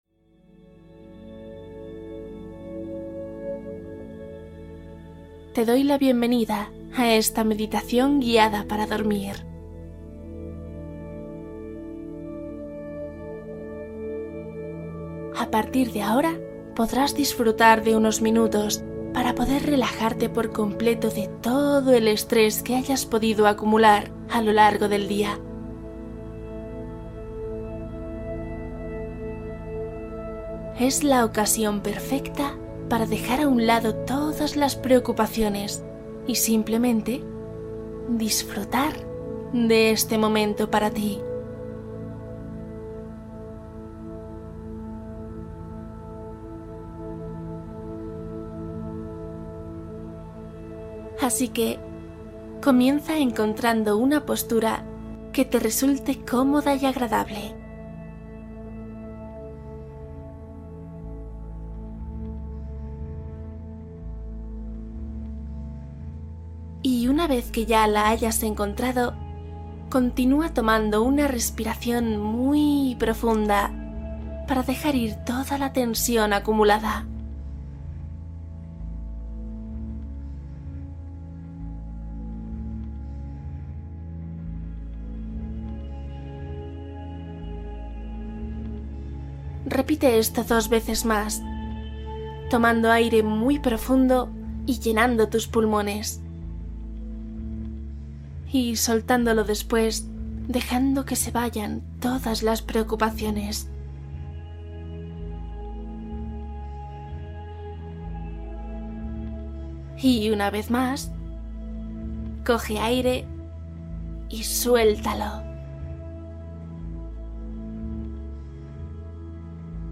Meditación hablada + música | Para dormir profundamente